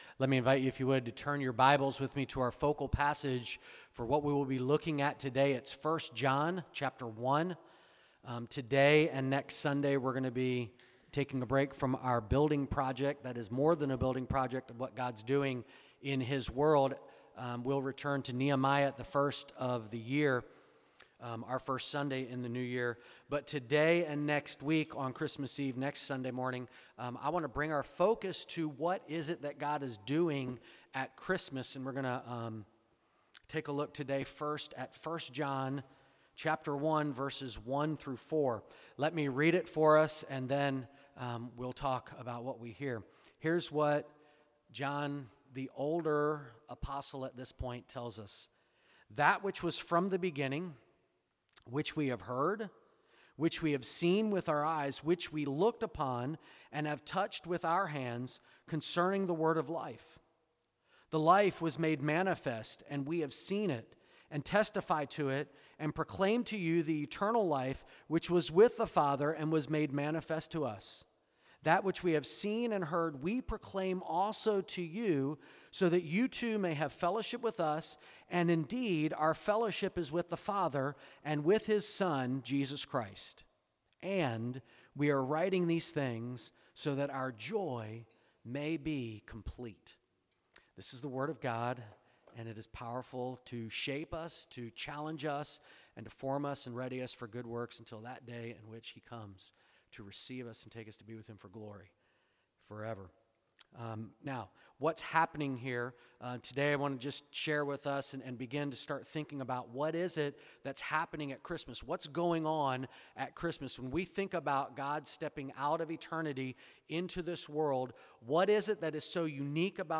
2017 Current Sermon What is Christmas?